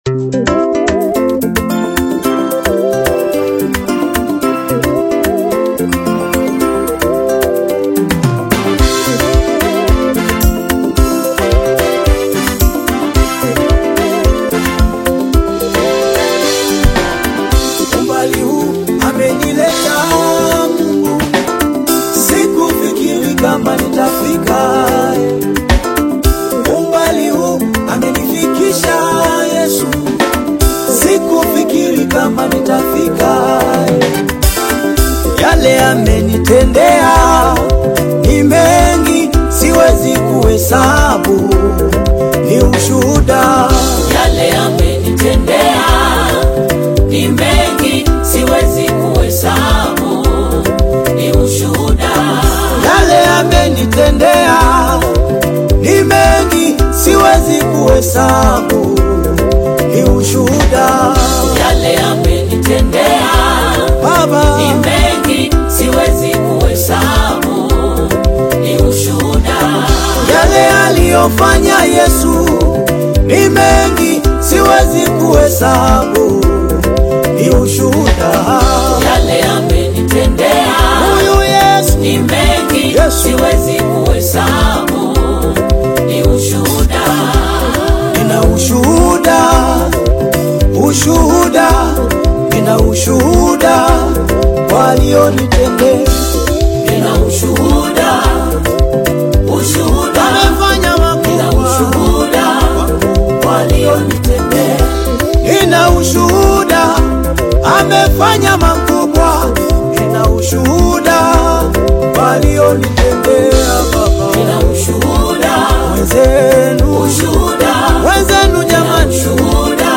Tanzanian gospel single
Swahili worship lyrics
soulful vocals and passionate ministry